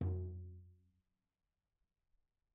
TomL_HitM_v2_rr2_Mid.mp3